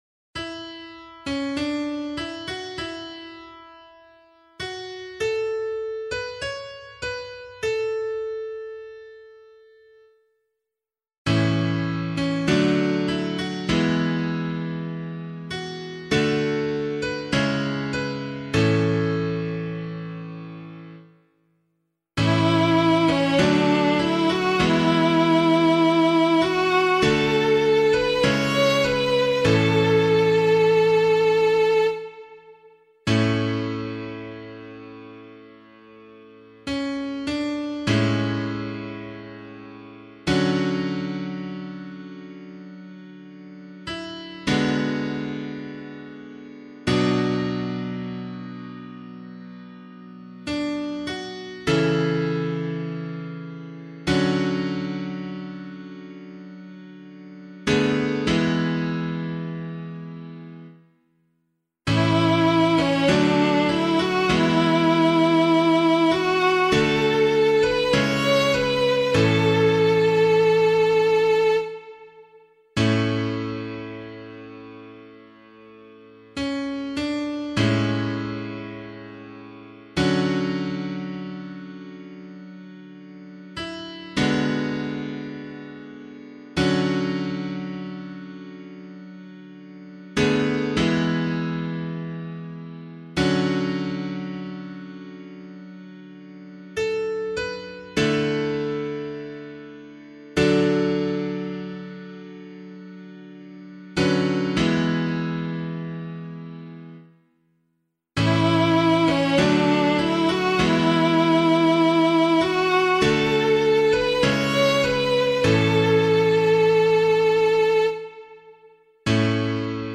The Holy Family of Jesus, Mary and Joseph: Responsorial Psalm, Year C option
011 Baptism of the Lord Psalm C [APC - LiturgyShare + Meinrad 8] - piano.mp3